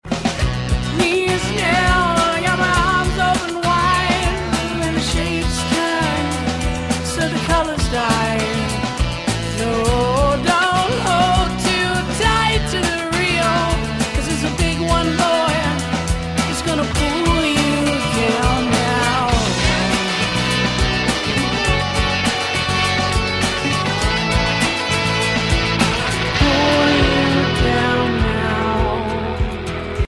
Chillout & Ambient